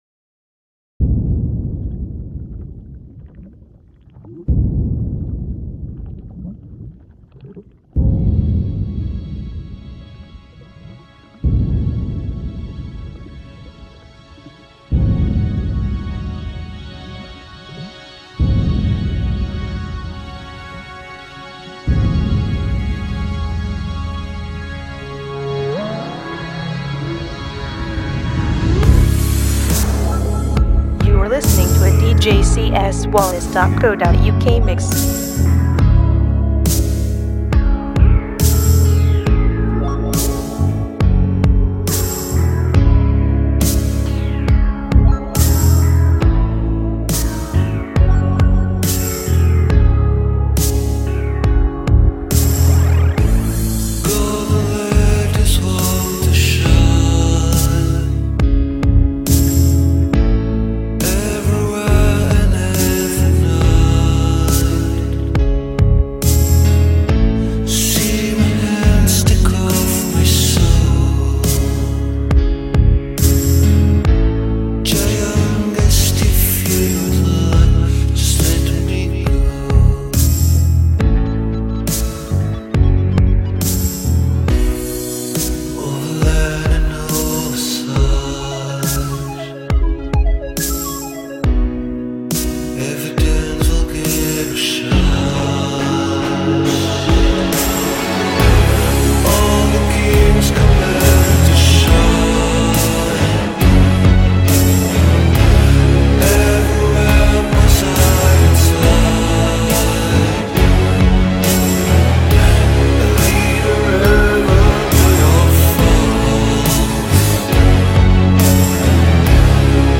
A little mix